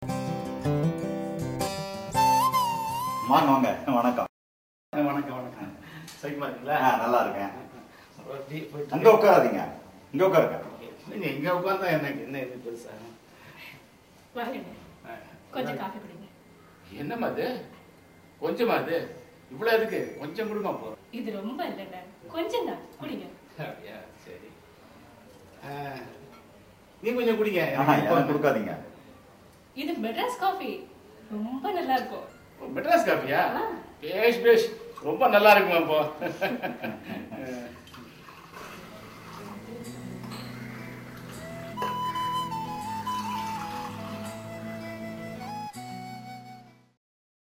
Unit 2, Dialogue 1